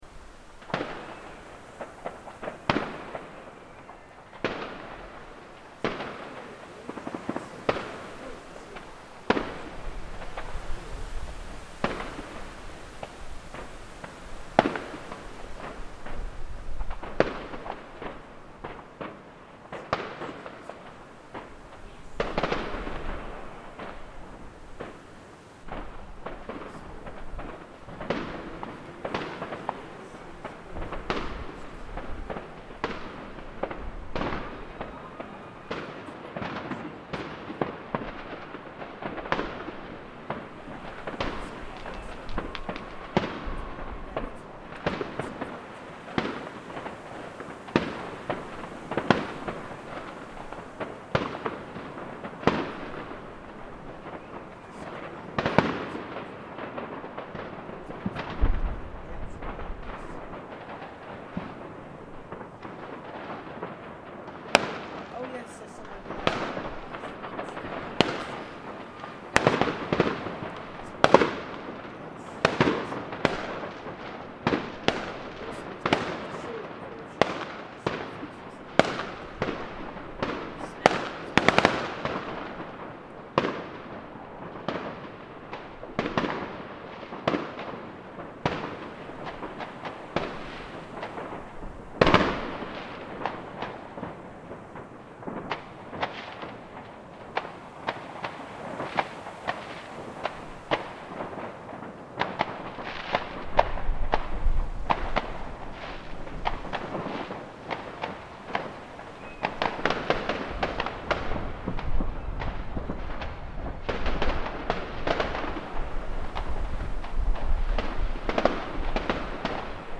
Neighbours' New Year fireworks recorded in my London garden
Use headphones or good speakers to hear this stereo recording at its best